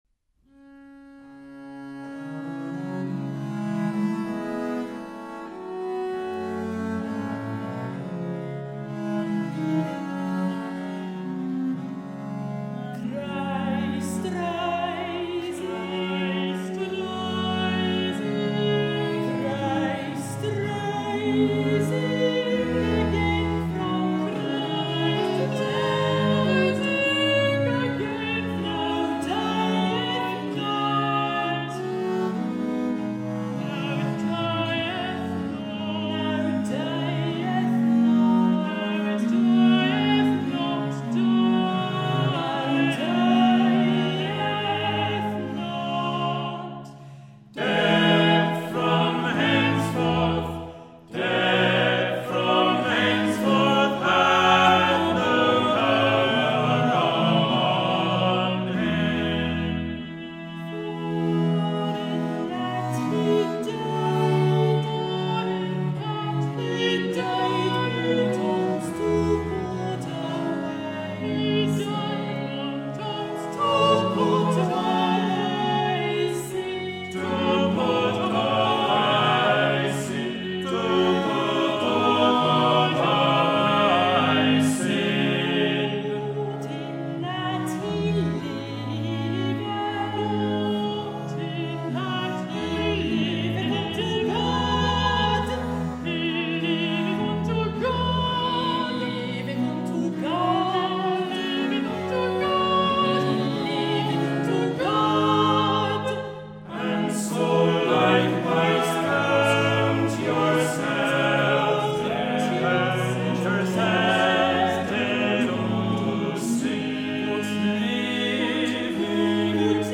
New recordings of Renaissance, Baroque and Classical repertoire have brought many of these ideas to life with performances that are exuberant, captivating, vibrant and joyful.
Verse Anthem: “Christ Rising Again from the Dead,” performed by Red Byrd and Rose Consort of Viols